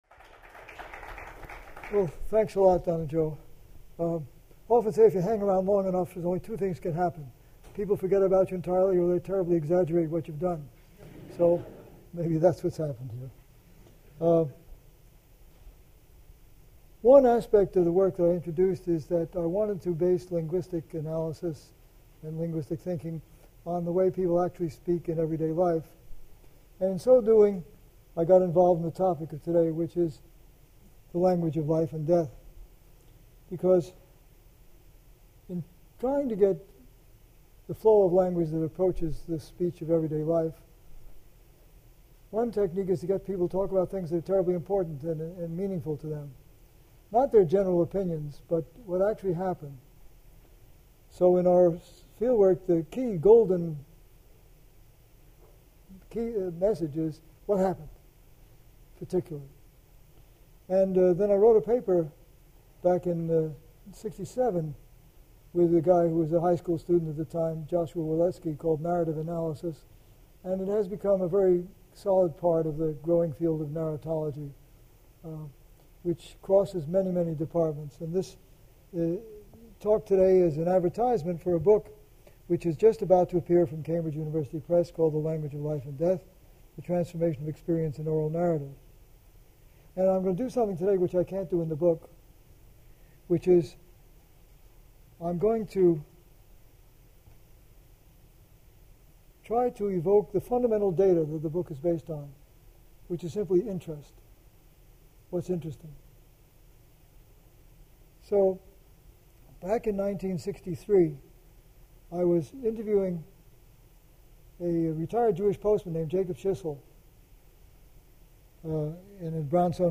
Linguist William Labov speaks on the language of life and death and the importance of oral storytelling modes.